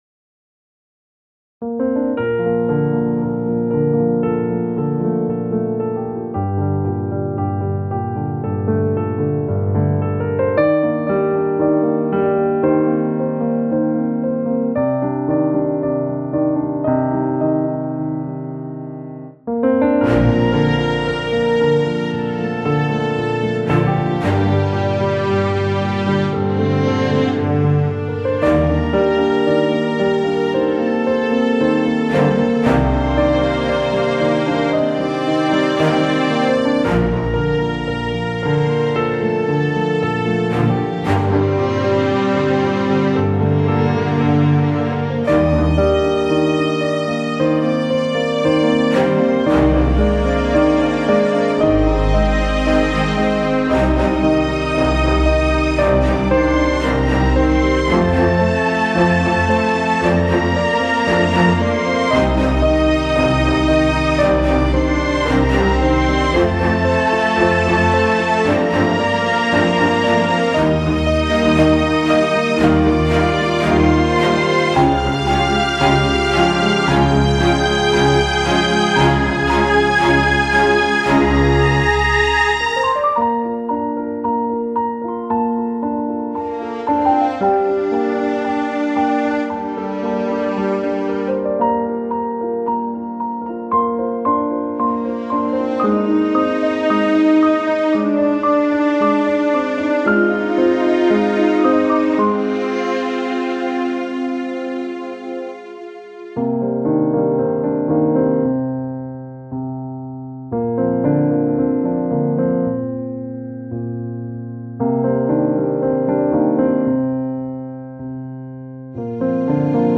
A calm and bright song full of optimism